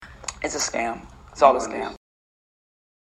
Brandon T. Jackson talks to Comedy Hype about Gucci mistreating Jim Jones.